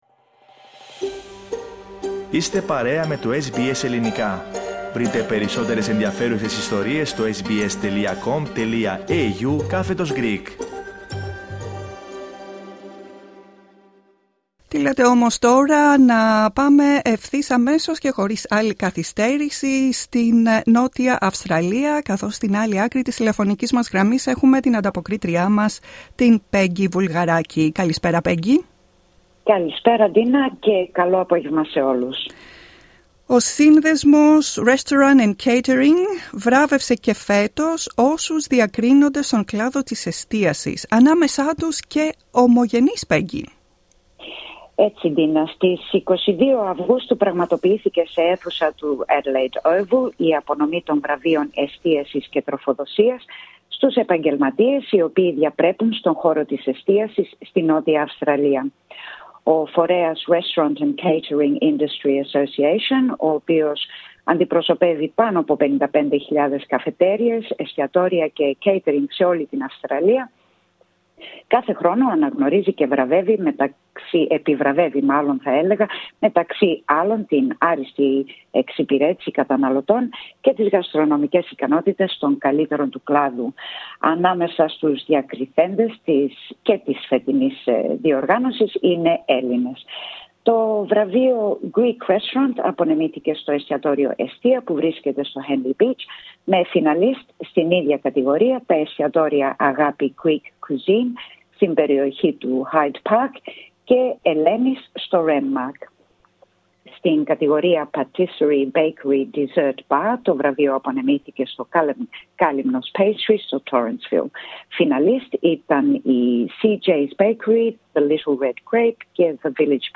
Η ομιλία ολοκληρώθηκε με ερωτήσεις από το κοινό.